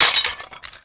ceramic_impact5.wav